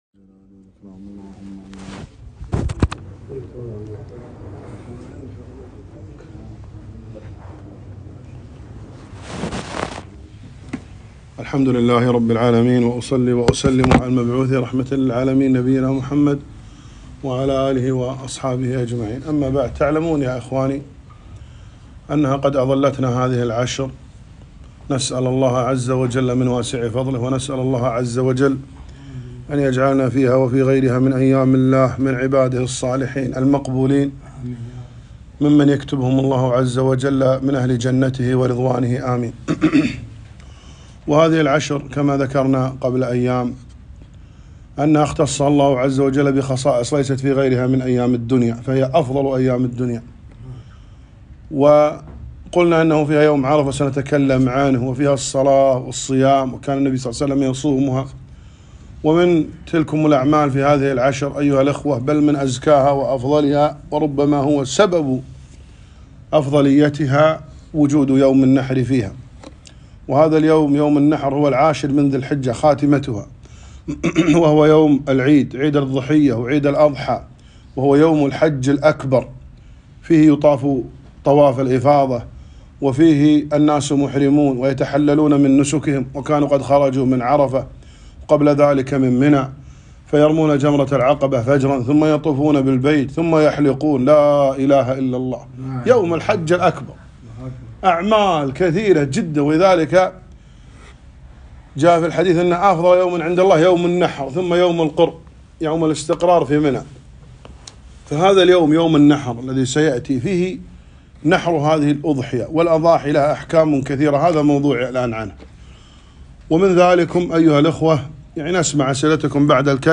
محاضرة - بعض الأحكام المختصرة في الأضحية